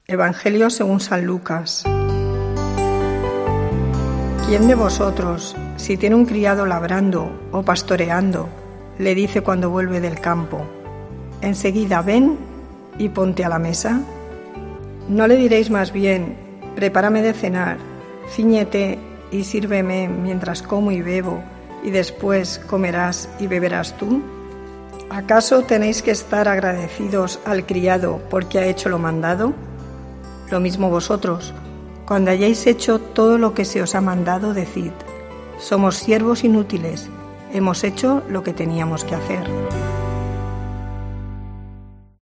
Lectura del santo evangelio según san Lucas 17,7-10